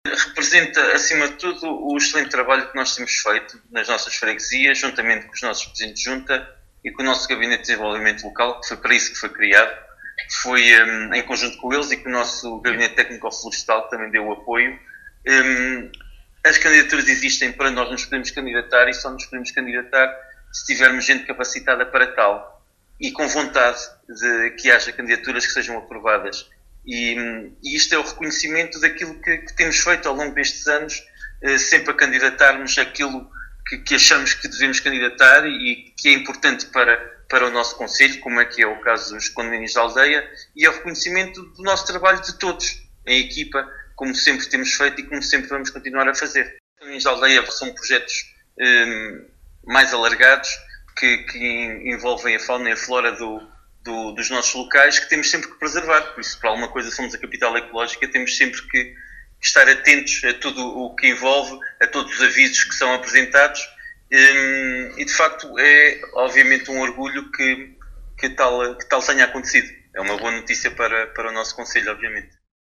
Paulo Marques, Presidente do Município de Vila Nova de Paiva, disse que estes dois projetos aprovados representam o reconhecimento do trabalho feito em em conjunto, por todos, “é uma boa notícia para o nosso concelho…”.